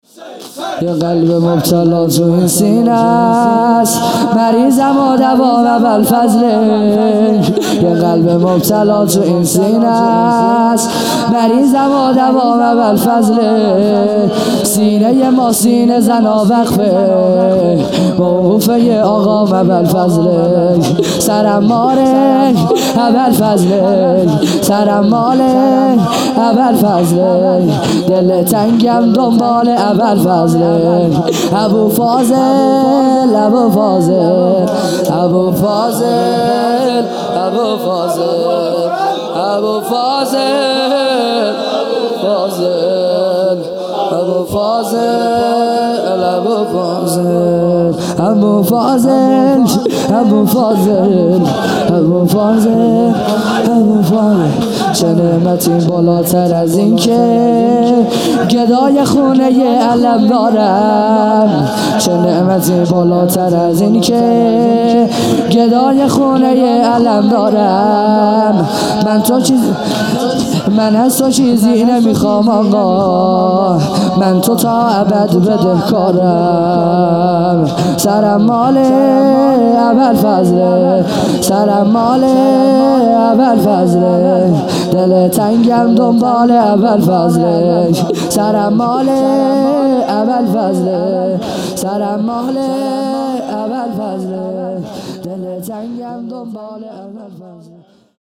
خیمه گاه - هیئت بچه های فاطمه (س) - شور | یه قلب مبتلا تو این سینهس
محرم 1441 | صبح نهم